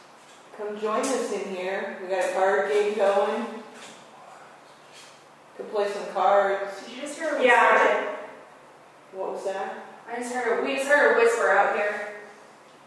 Evidence from the Old Blackford County Jail
This one creeps me out because clearly we also heard it real time! We looked all around after hearing it, and there wasn’t anyone else nearby.